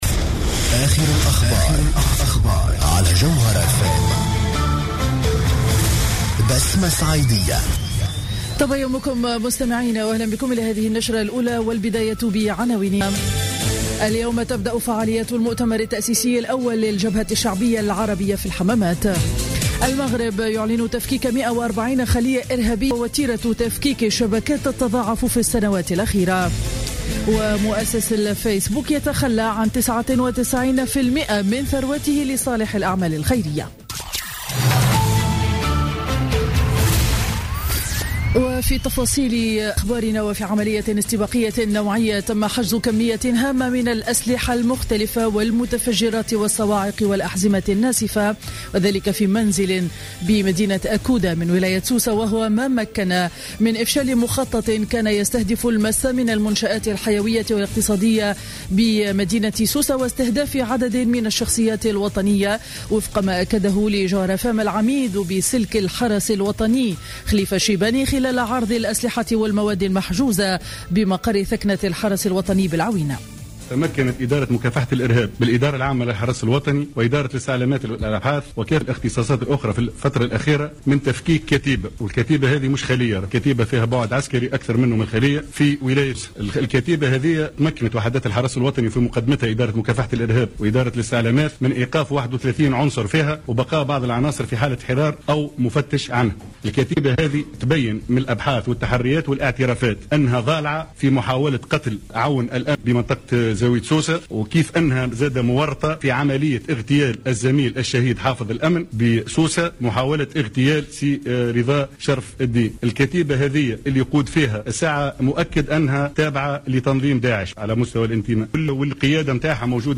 نشرة أخبار السابعة صباحا ليوم الأربعاء 2 ديسمبر 2015